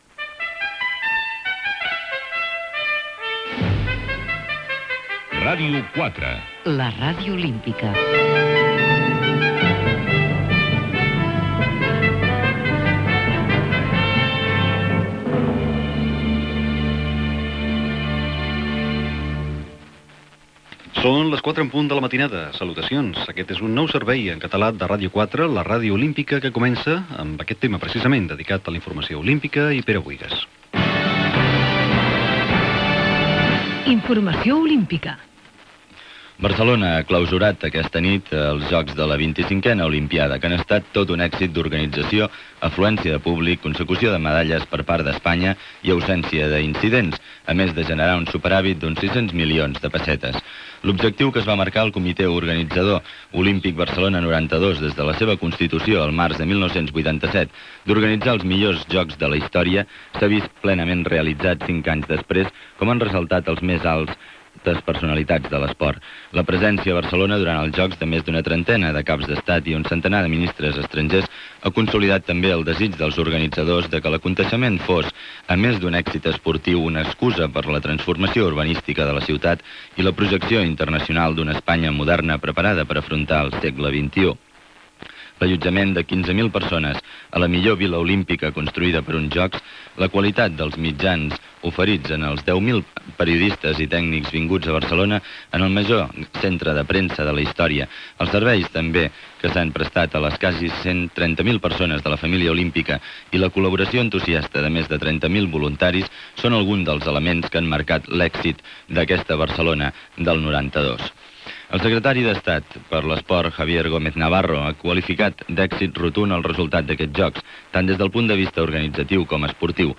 resum dels Jocs Olímpics de Barcelona i de la cerimònia de cloenda, indicatiu multilingüe, cançó, informació internacional, hora, tema musical, informatiu en anglès
Gènere radiofònic Informatiu